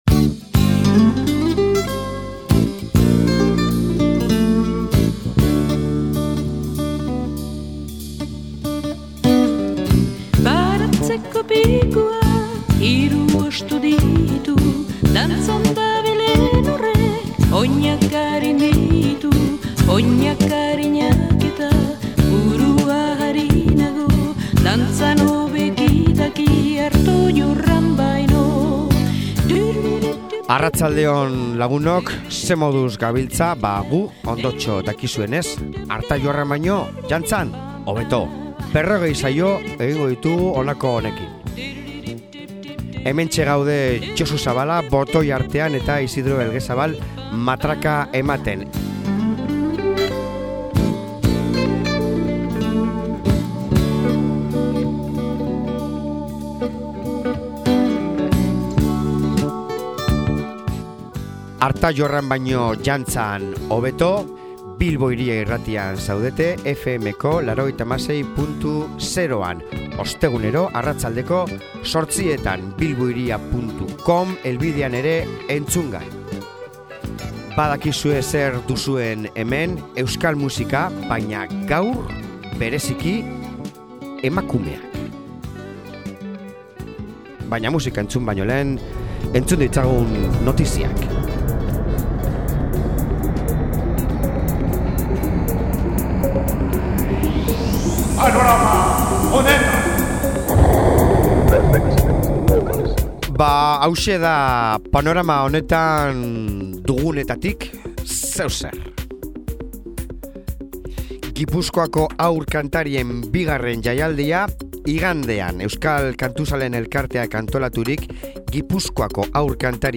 Gaur… emakume ahotsak!